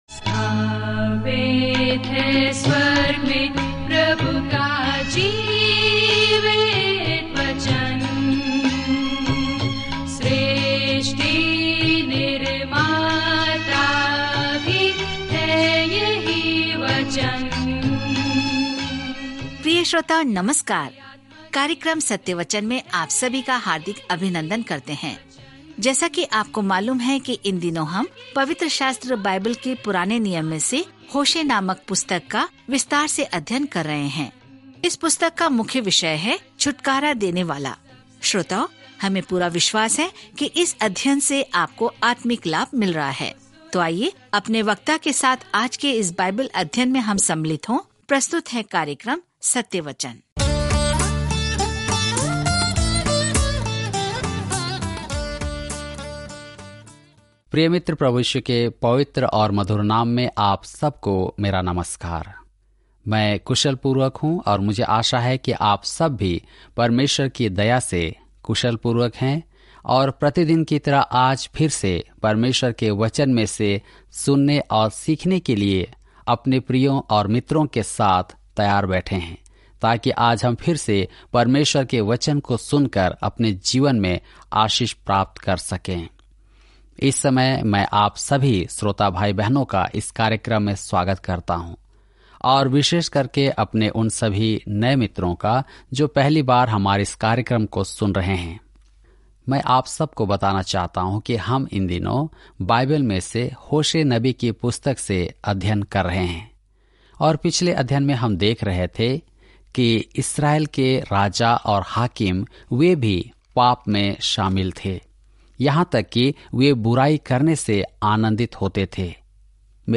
पवित्र शास्त्र होशे 8 होशे 9:1-6 दिन 11 यह योजना प्रारंभ कीजिए दिन 13 इस योजना के बारें में परमेश्वर ने होशे के दर्दनाक विवाह का उपयोग एक उदाहरण के रूप में किया कि जब उसके लोग उसके प्रति विश्वासघाती होते हैं तो उसे कैसा महसूस होता है, फिर भी वह उनसे प्रेम करने के लिए प्रतिबद्ध है। जब आप ऑडियो अध्ययन सुनते हैं और भगवान के वचन से चुनिंदा छंद पढ़ते हैं तो होशे के माध्यम से दैनिक यात्रा करें।